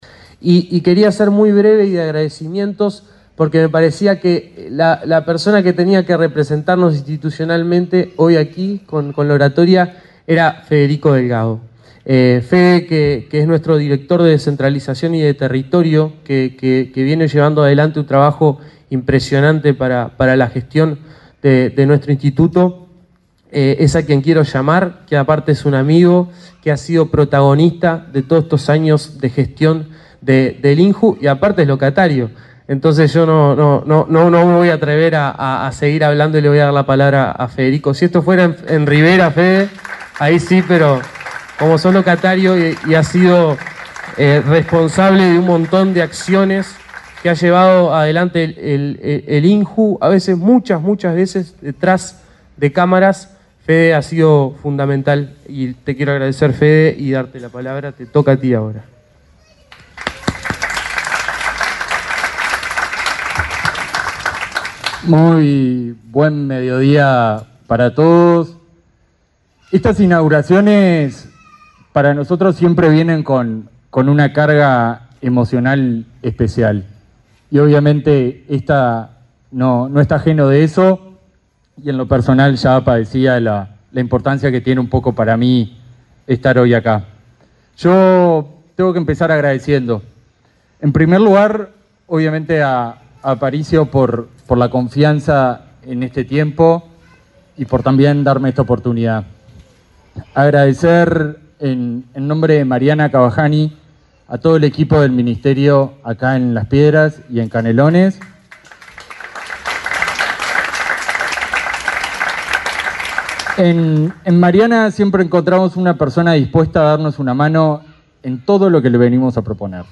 Palabras de autoridades del INJU
Palabras de autoridades del INJU 19/12/2024 Compartir Facebook X Copiar enlace WhatsApp LinkedIn El director del Instituto Nacional de la Juventud (INJU), Aparicio Saravia, y el director de Descentralización de ese organismo, Federico Delgado, encabezaron la inauguración de un centro Ni Silencio Ni Tabú en la localidad de Las Piedras, departamento de Canelones.